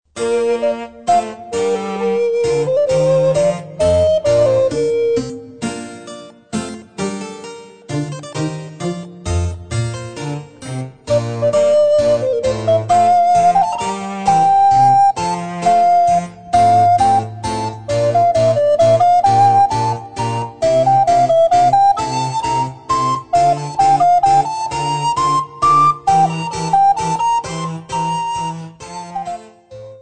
Besetzung: Altblockflöte und Basso continuo